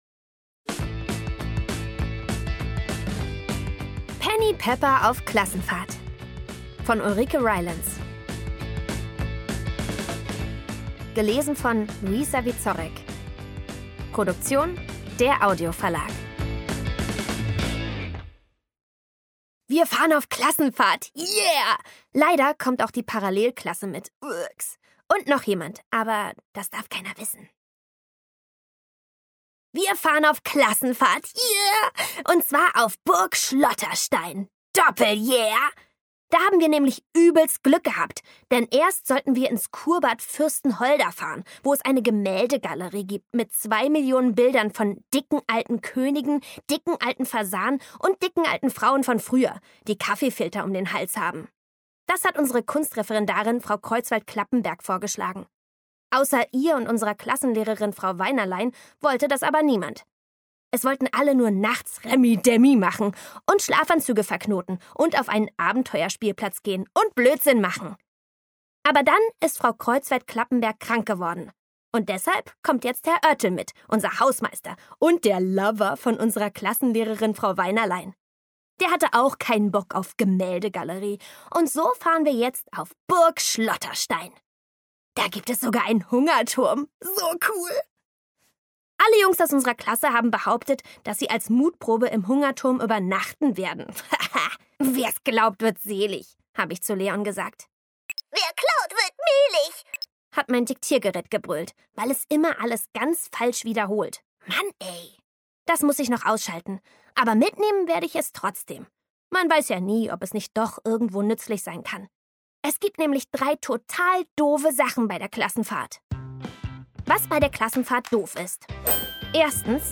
Penny Pepper – Teil 6: Auf Klassenfahrt Szenische Lesung mit Musik